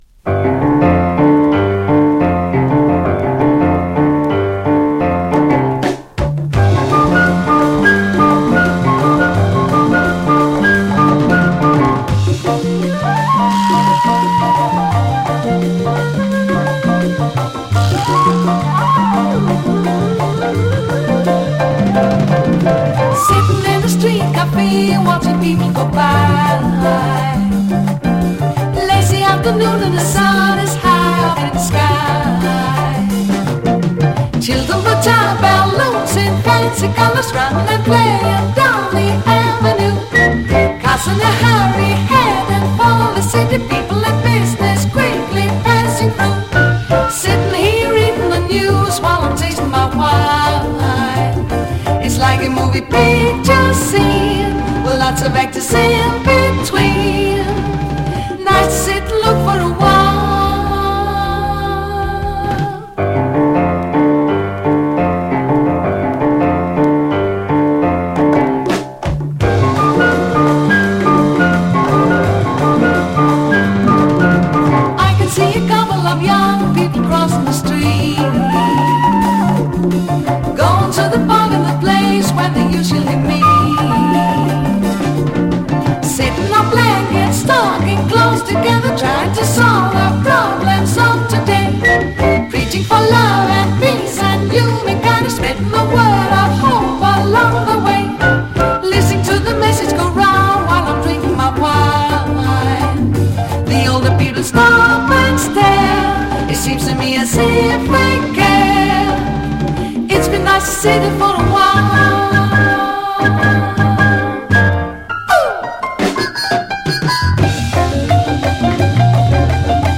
Latin sweden